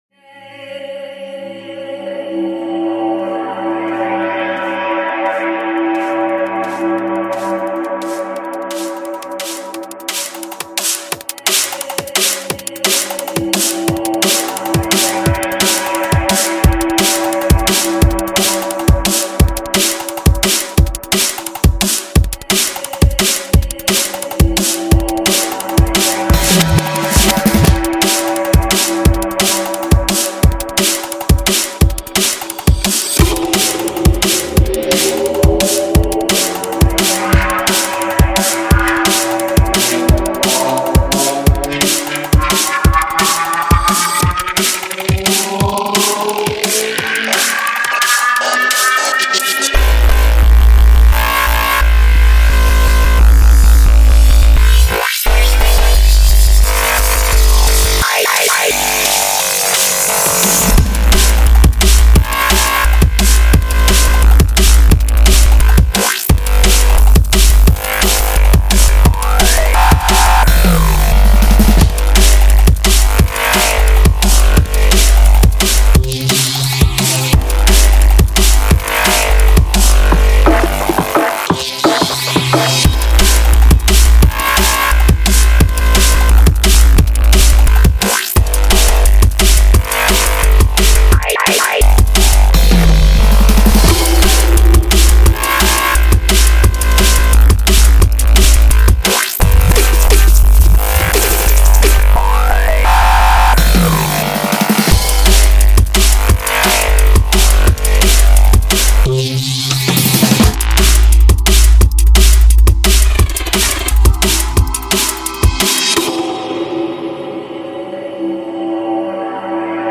6天前 Drum Bass · Neurofunk 42 推广
您将获得 100 个音色如同 Transformers 般劲爆的神经贝斯循环和 119 个带有音调标记的贝斯单音采样
也可以使用我们提供的 100 个单音鼓组采样（包括底鼓、军鼓、踩镲、镲片和打击乐器）来创作您自己的创意鼓点。当然，鼓组部分也少不了鼓过门。
我们还加入了 23 个暗黑氛围合成器音垫和 19 个特效音效，可用于贝斯音轨的开头和结尾。速度最高可达 174bpm。